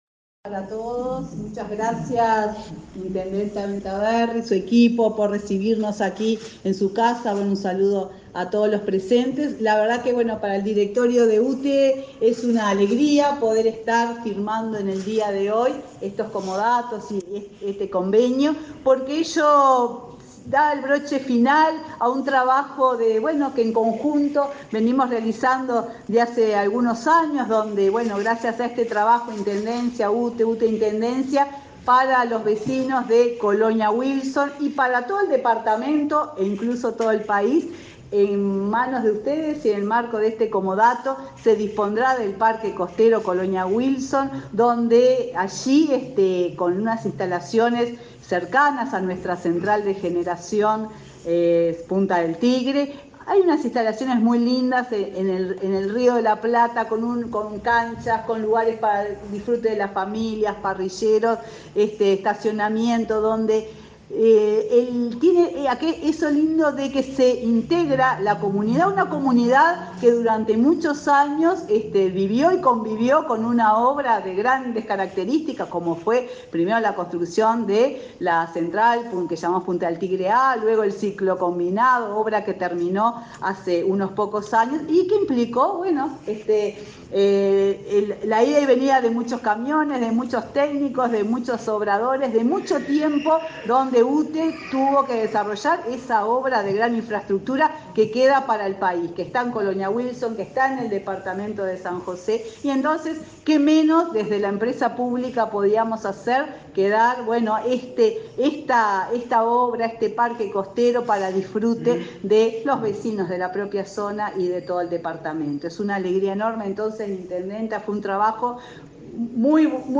Palabras de la presidenta de UTE, Silvia Emaldi
Palabras de la presidenta de UTE, Silvia Emaldi 12/08/2022 Compartir Facebook X Copiar enlace WhatsApp LinkedIn La empresa UTE y la Intendencia de San José acordaron un comodato. En el acto, realizado este 12 de agosto en la capital departamental, participó la presidenta del organismo estatal, Silvia Emaldi.